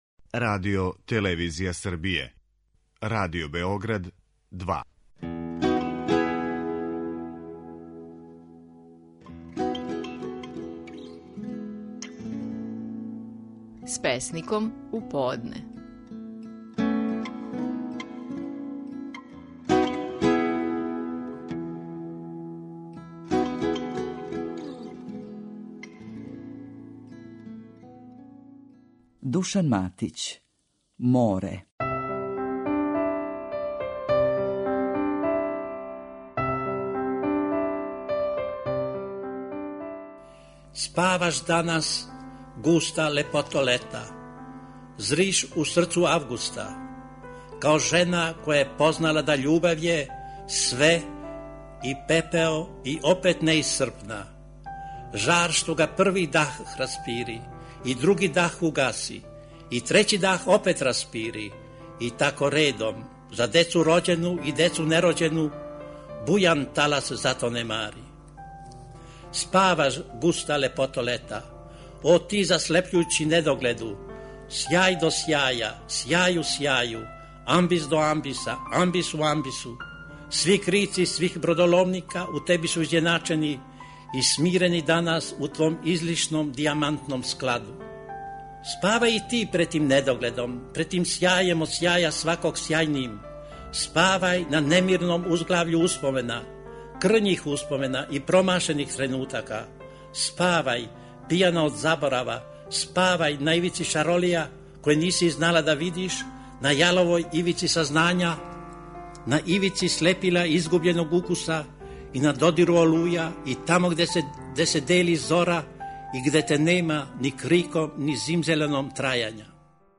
Наши најпознатији песници говоре своје стихове
Душан Матић говори своју песму "Море".